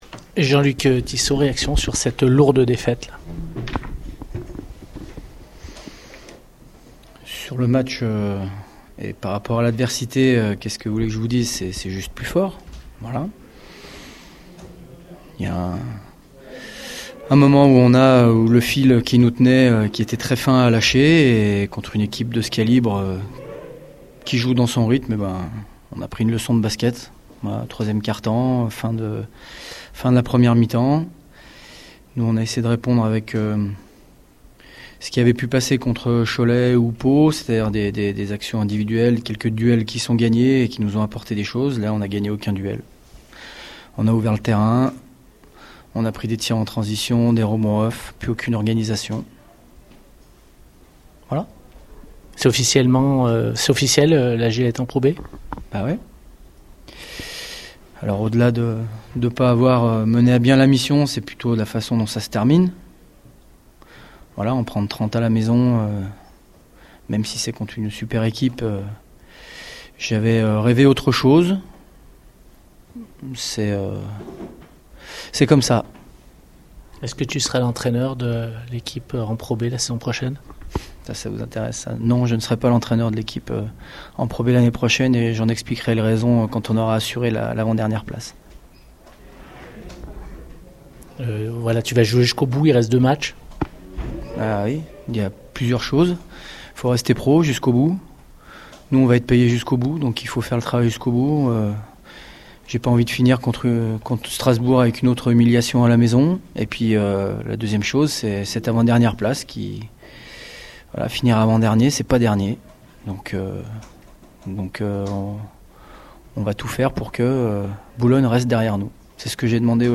On écoute les réactions au micro Scoop